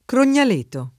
Crognaleto [ kron’n’al % to ]